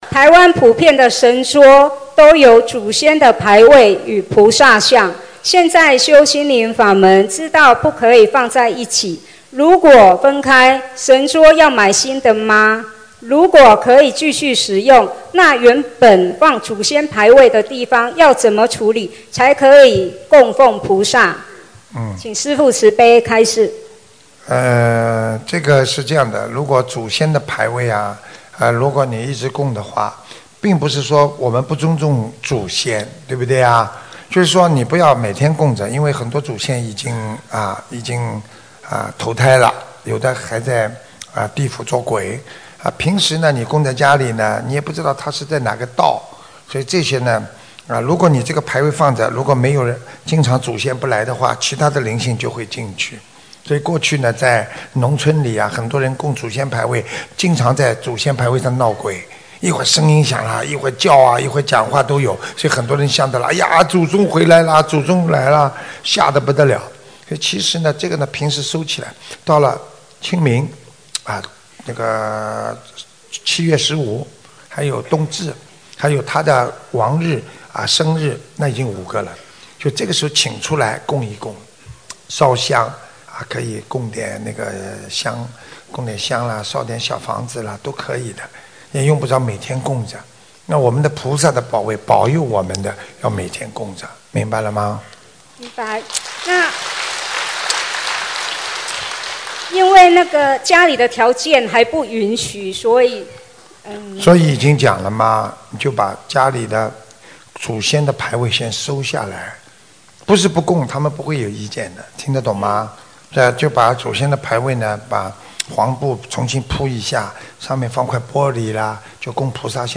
供过祖先牌位的神桌，能否用来设佛台┃弟子提问 师父回答 - 2017 - 心如菩提 - Powered by Discuz!